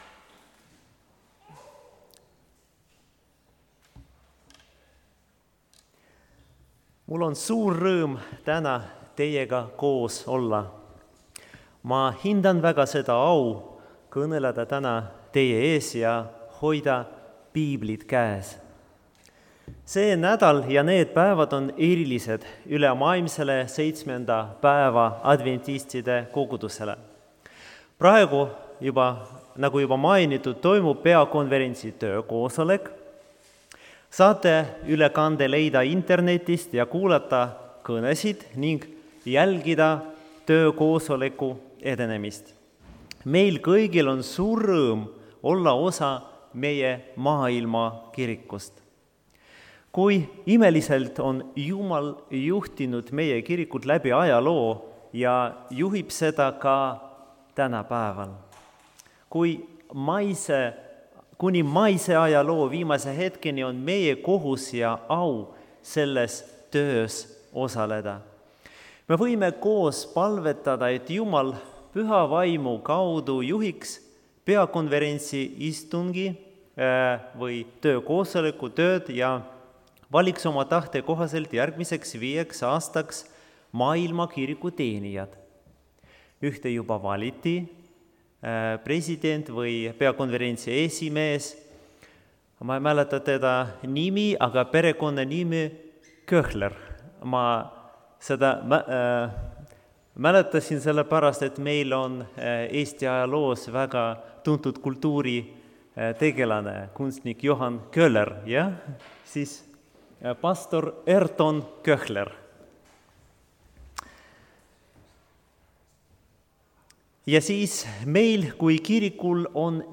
prohvet Elisa elus (Tallinnas)
Jutlused